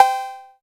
088 - CowbelSy.wav